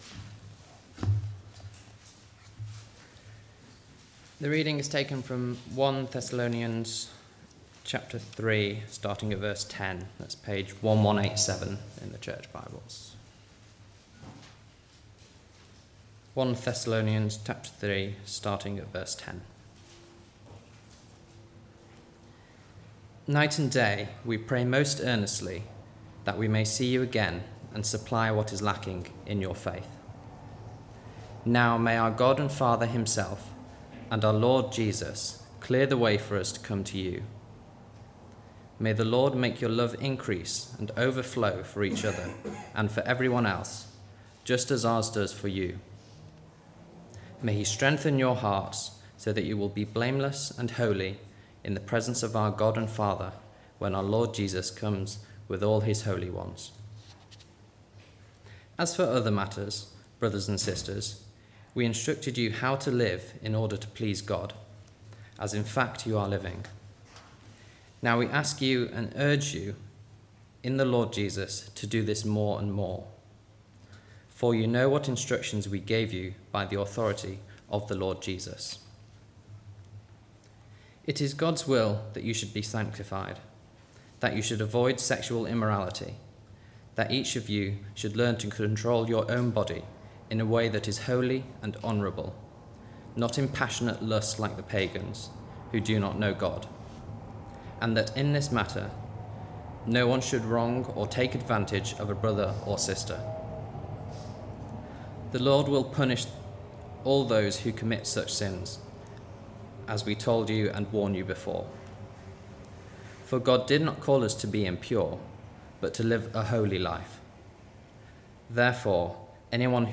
Sermon-13th-November-22.mp3